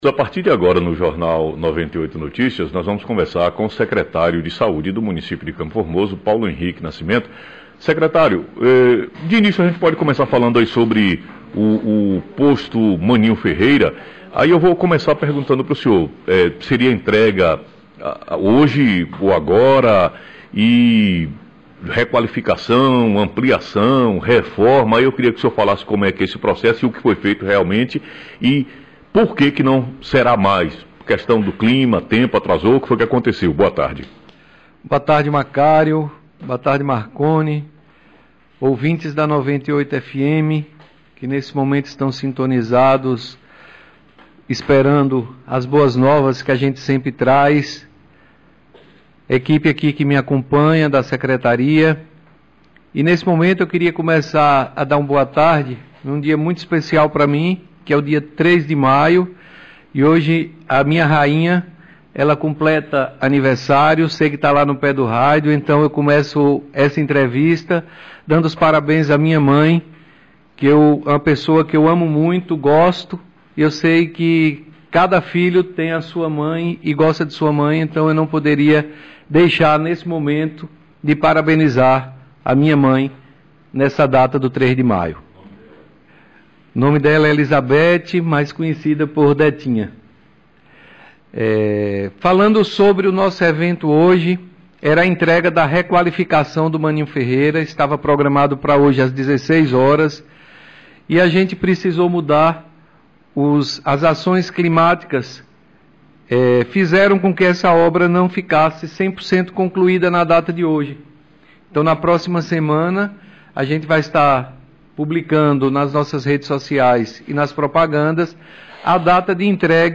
Entrevista com o Secretário de saúde Paulo Henrique nascimento